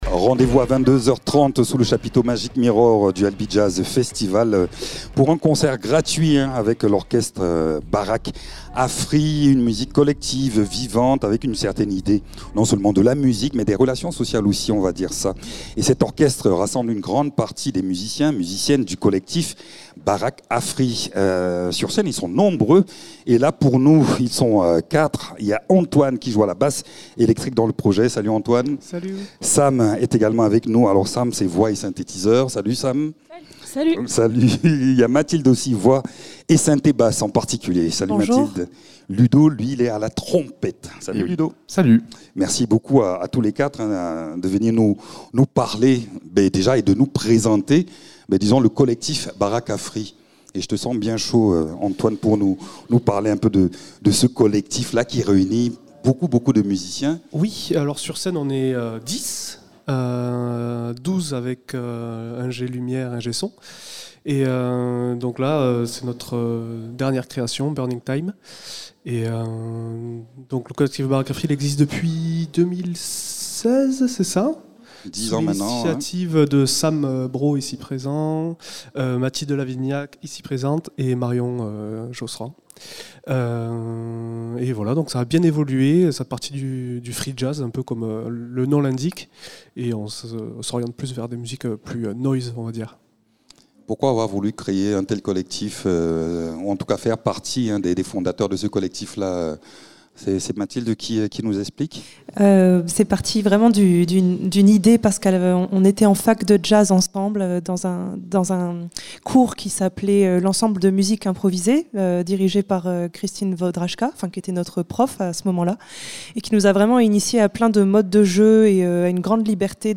Avec des inspirations pop et techno, c’est exaltant ! Ils portent une attention particulière à la création coopérative, à l’écoute de chacun.e.
Invité(s) : Collectif Orchestre Baraque à Free